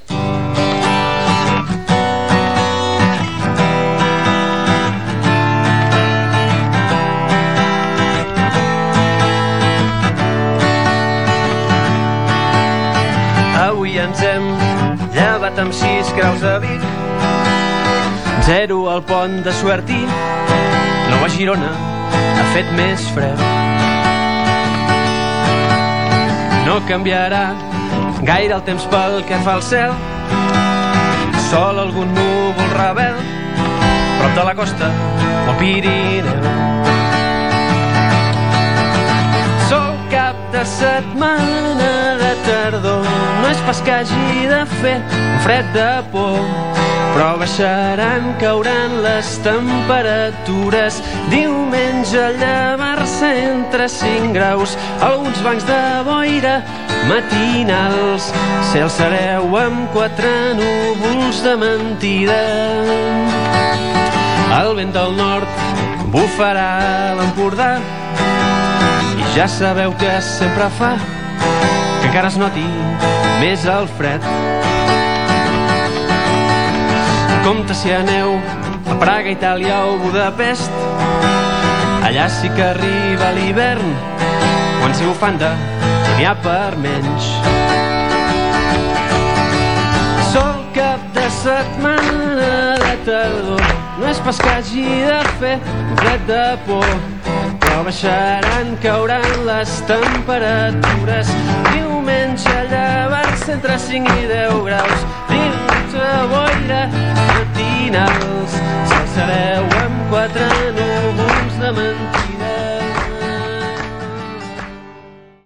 Previsió del temps cantada. Gènere radiofònic Informatiu